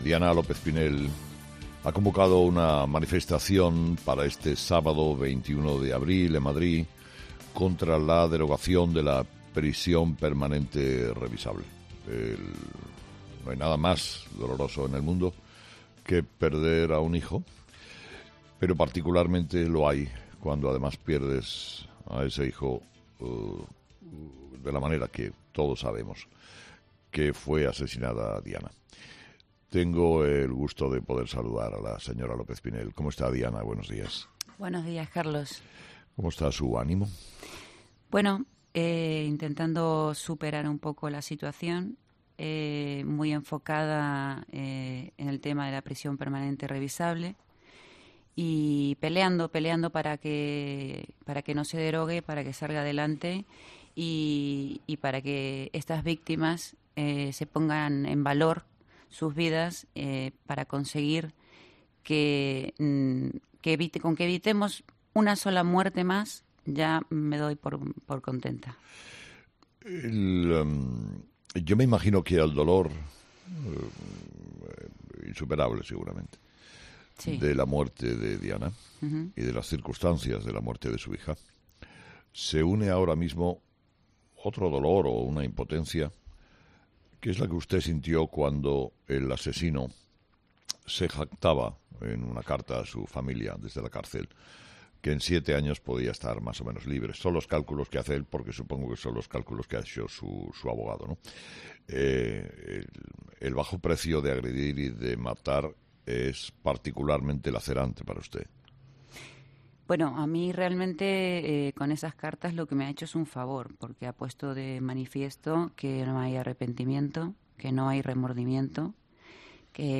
ha sido entrevistada este miércoles en 'Herrera en Cope'